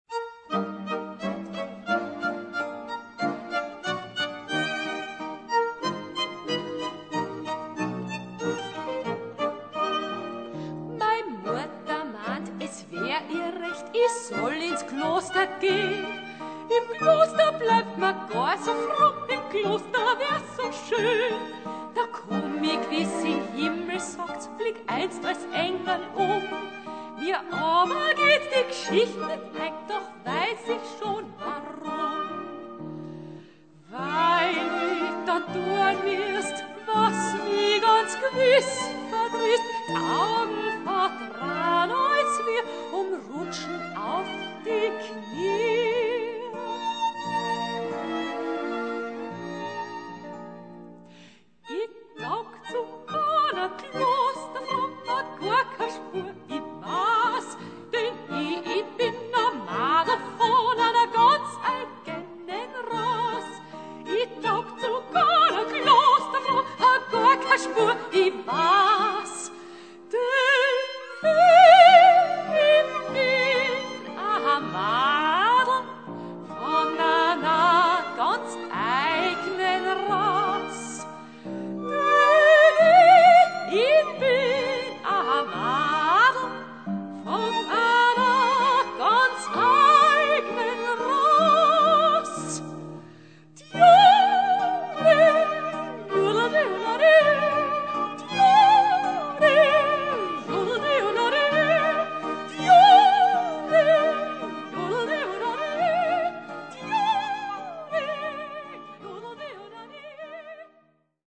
Kontragitarre
Knopfharmonika
G-Klarinette